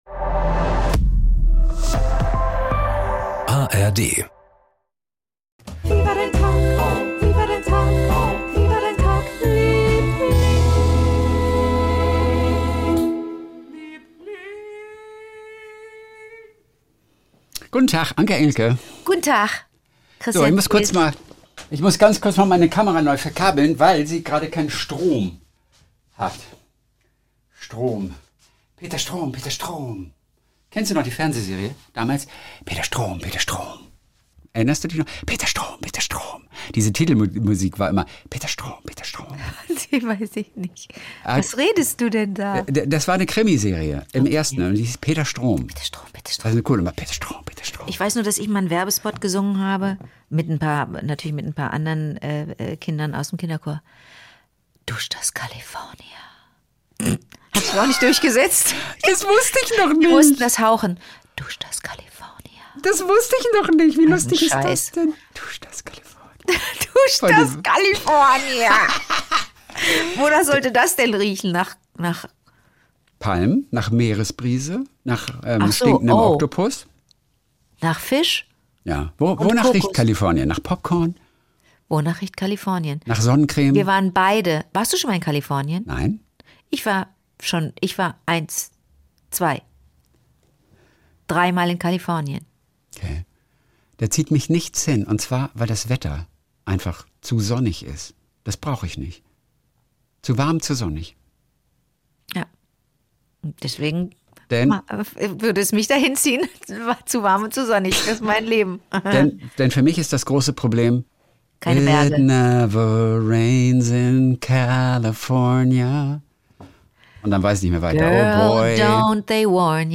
Die kleinen Geschichtchen des Tages mit Anke Engelke und SWR3-Moderator Kristian Thees.
… continue reading 483 bölüm # Gesellschaft # SWR3 # Kristian Thees # Wie War Der Tag # Liebling # Comedy # Saubere Komödien # Unterhaltung # Komödie # Anke Engelke